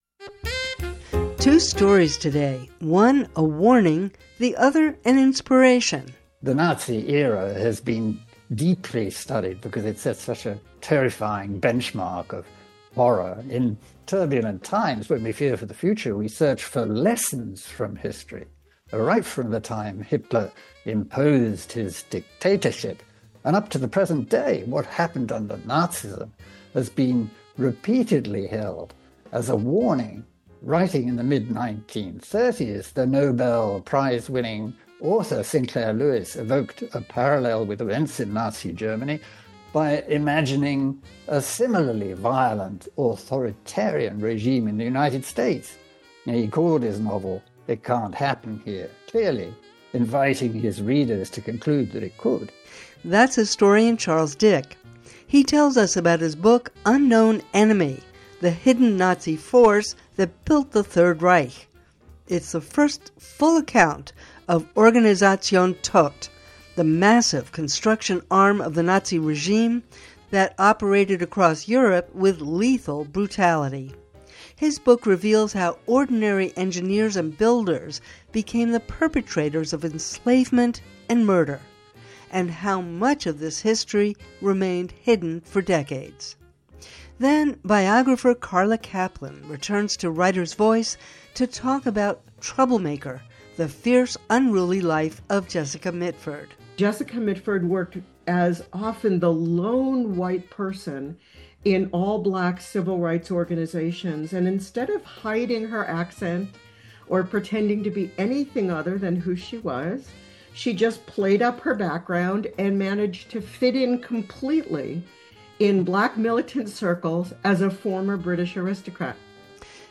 Writer’s Voice: compelling conversations with authors who challenge, inspire, and inform.